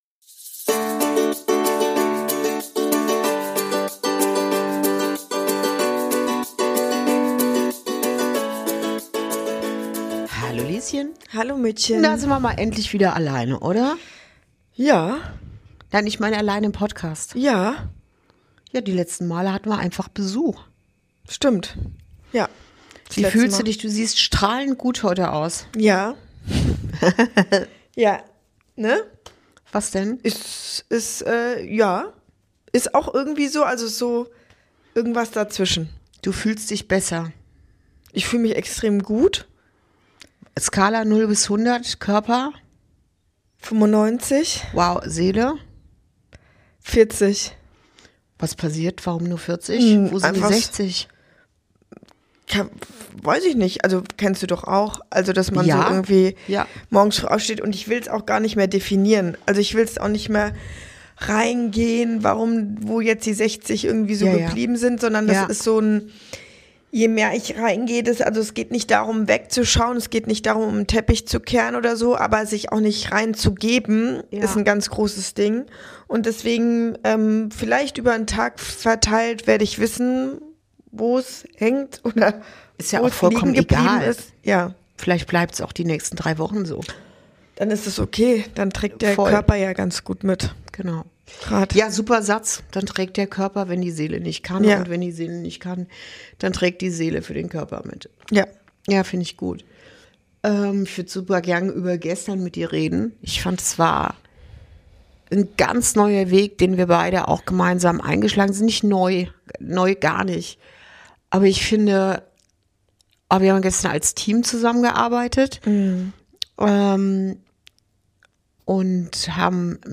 058: Bewusstsein statt Methode - Veränderung beginnt im Innen ~ Inside Out - Ein Gespräch zwischen Mutter und Tochter Podcast